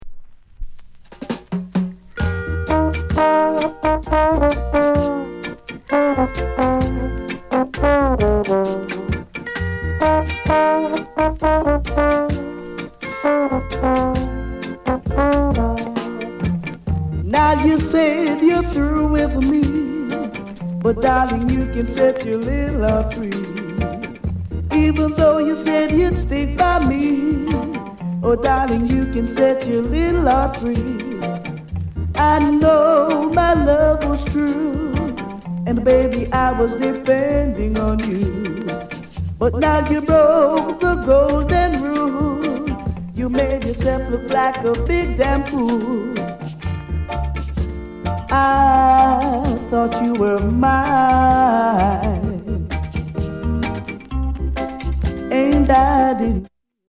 ROOTS